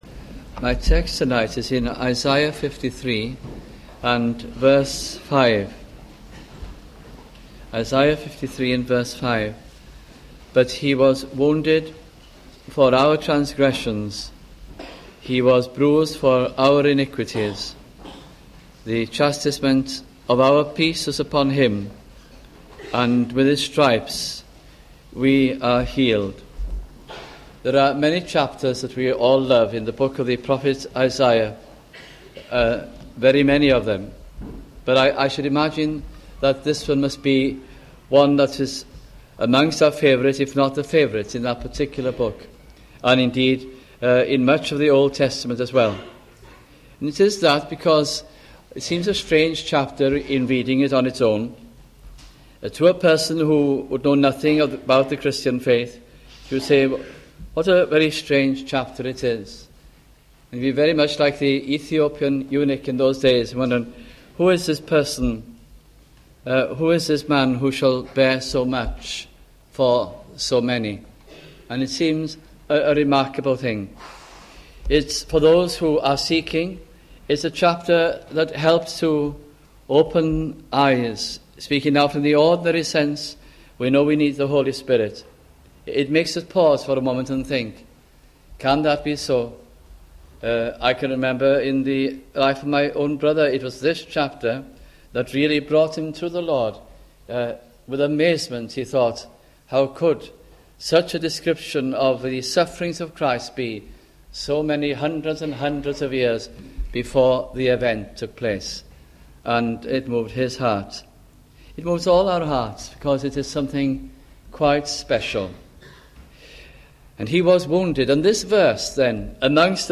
» Isaiah Gospel Sermons